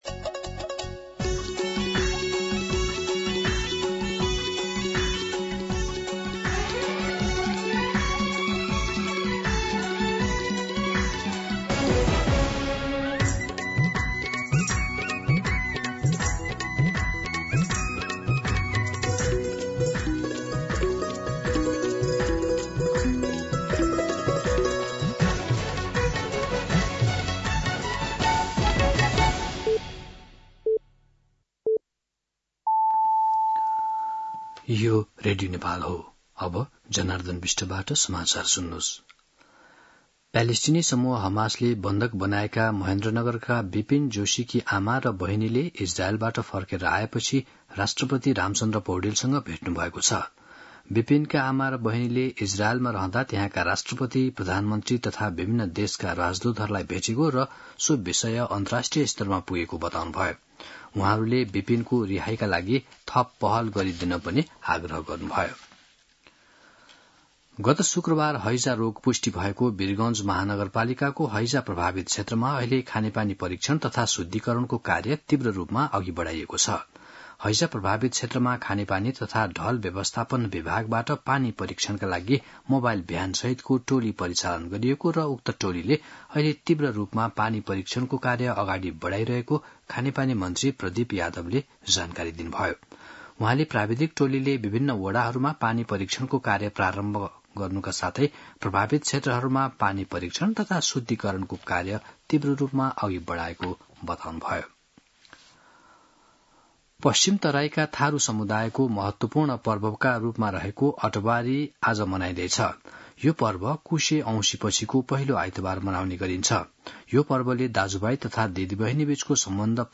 दिउँसो १ बजेको नेपाली समाचार : ८ भदौ , २०८२